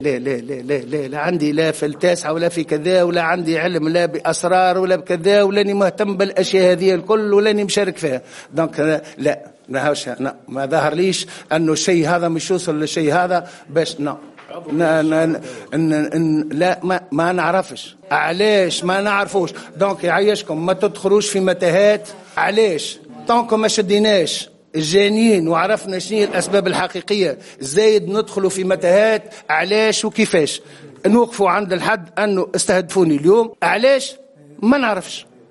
أكد النائب عن حركة نداء تونس رضا شرف الدين خلال الندوة الصحفية المنعقدة حاليا ان محاولة الإغتيال التي تعرض لها اليوم الخميس لا علاقة لها بقناة التاسعة لا من قريب ولا من بعيد .